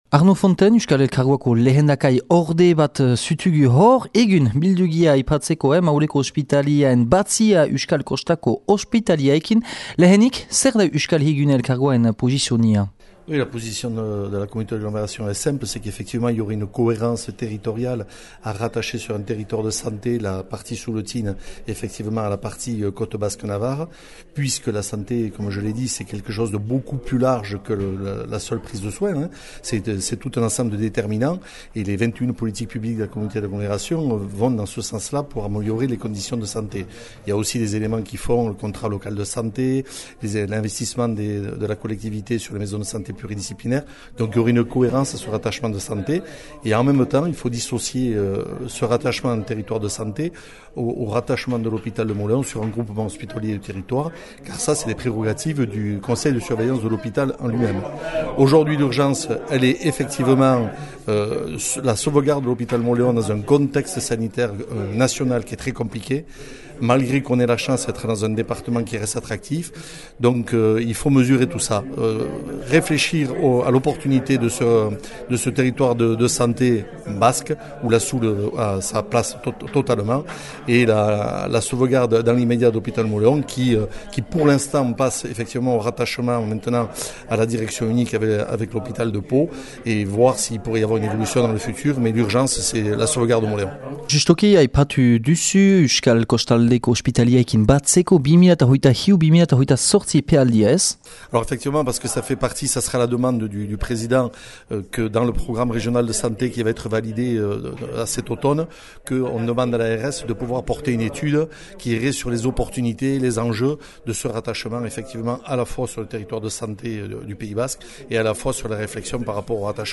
Üngürgiro hortan dü antolatü EH Bai alderdiak Ospitalearen geroaz mahain üngürü bat neskenegünean.